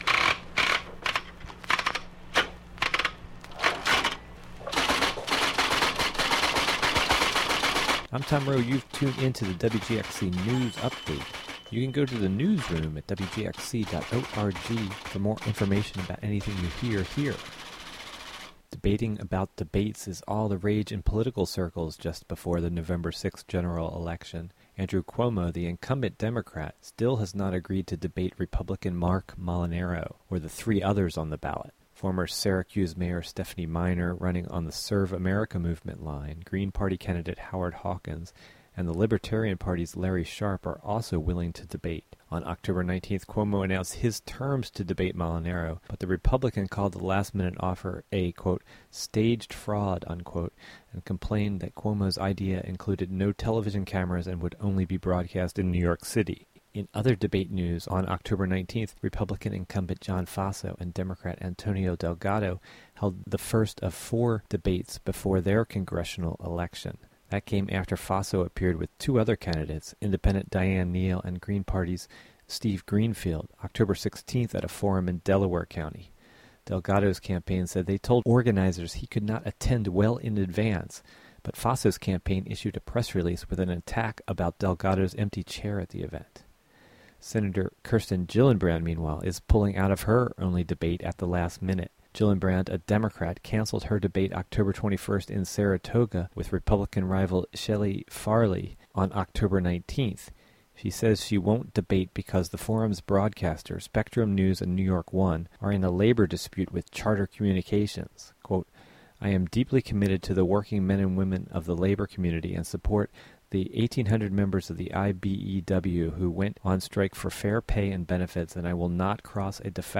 WGXC Local News Audio Link